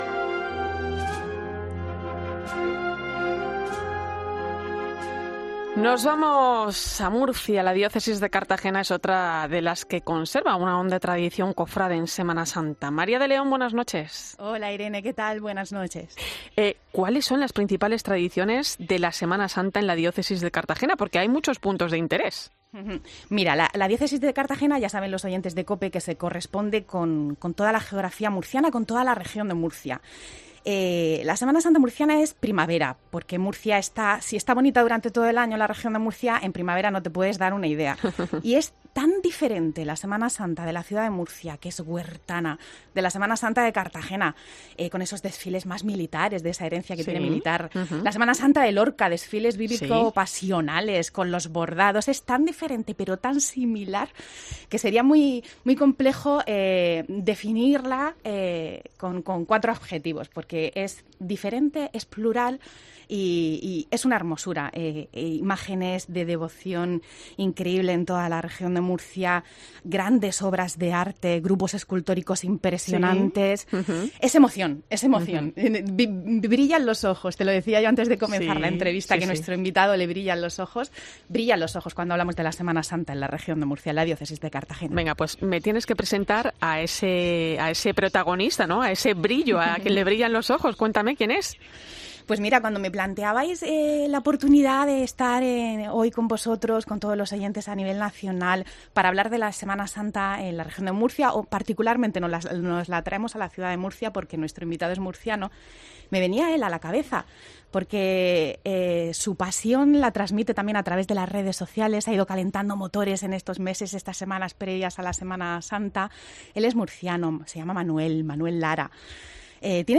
Hablamos con un cofrade de la ciudad de Murcia que participa en 4 de las 15 hermandades de la ciudad